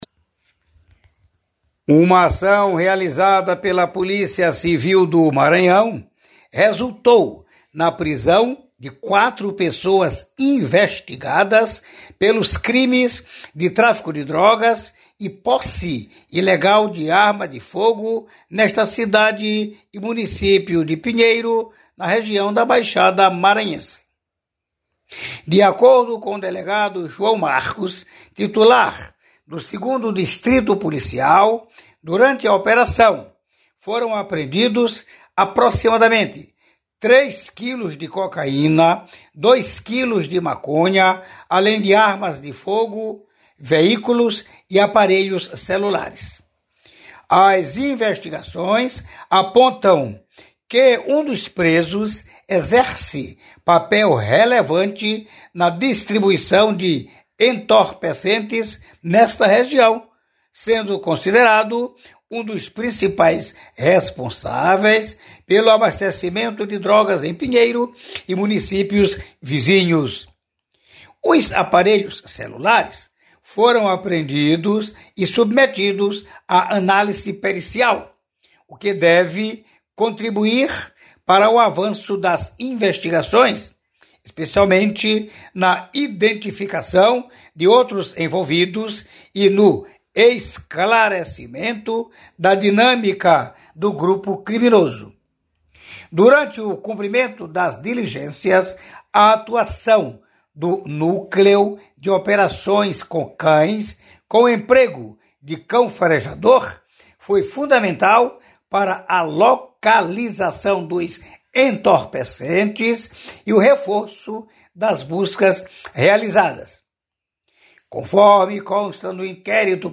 ÁUDIO: Quatro são presos com drogas e armas em Pinheiro